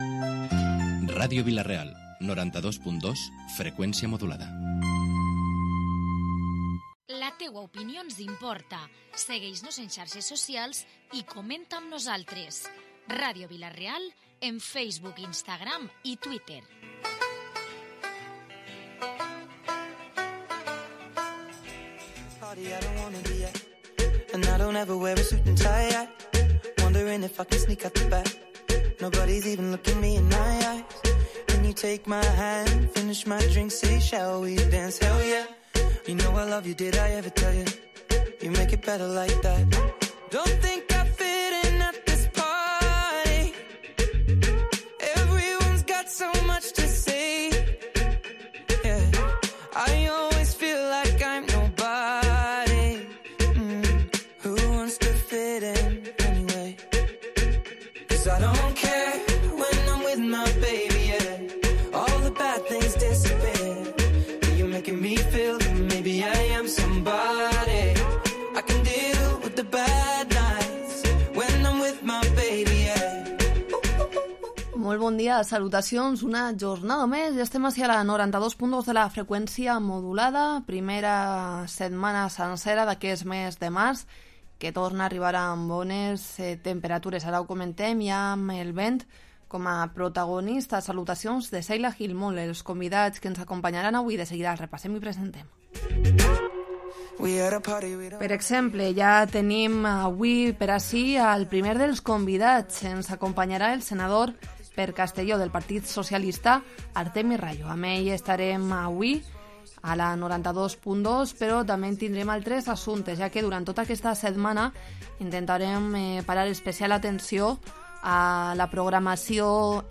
Hoy en Protagonistes hemos entrevistado al senador del PSPV por Castellón, Artemi Rallo, que nos ha explicado los motivos para eliminar el voto rogado y ha repasado actualidad política nacional.